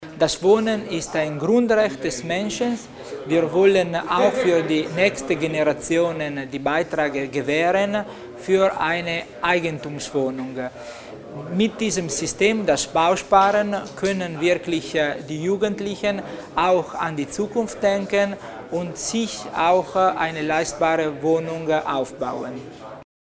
Landesrat Tommasini erläutert die Details der Bausparen-Vereinbarungen